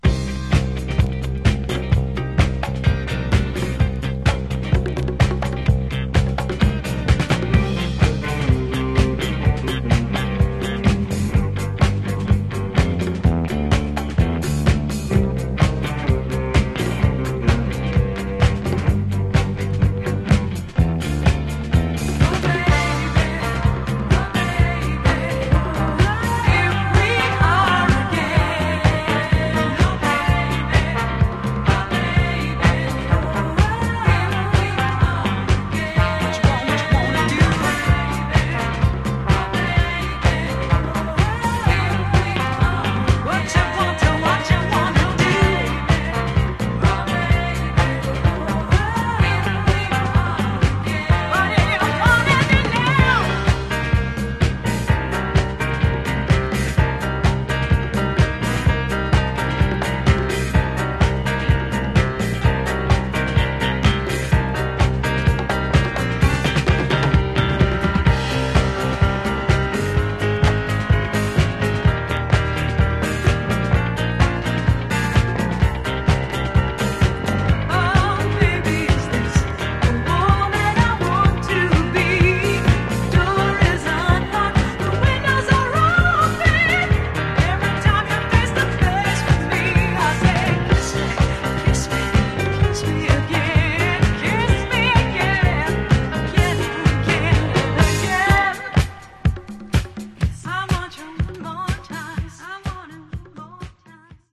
Genre: Modern Rock